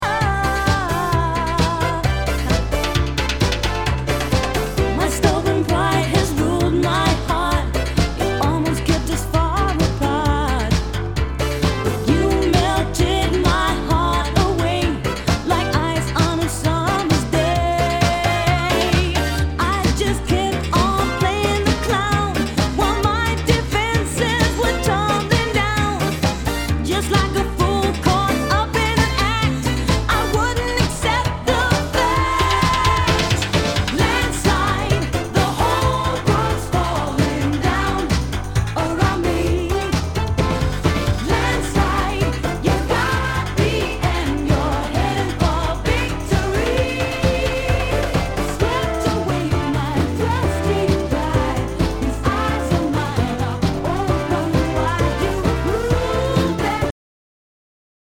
SOUL/FUNK/DISCO
ナイス！ハイエナジー / シンセ・ポップ・ディスコ！
ジャケにスレキズ、ヨゴレ、シールの貼り付けあり 全体にチリノイズが入ります